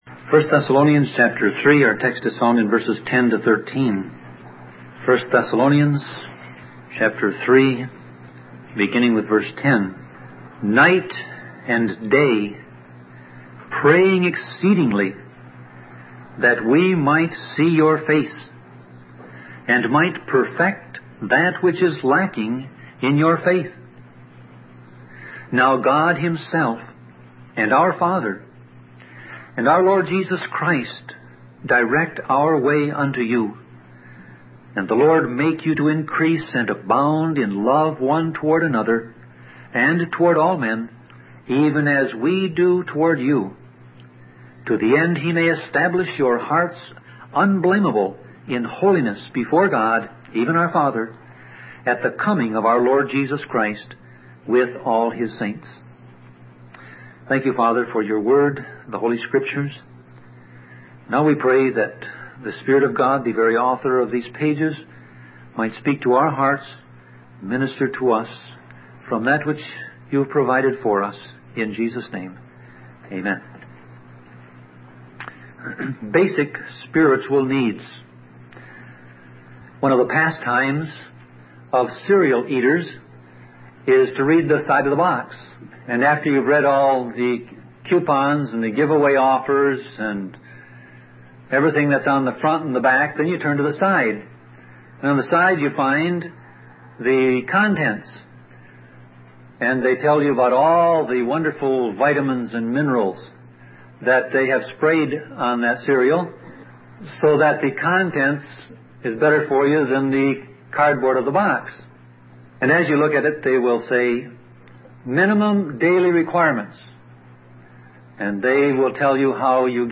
Series: Sermon Audio Passage: 1 Thessalonians 3:10-13 Service Type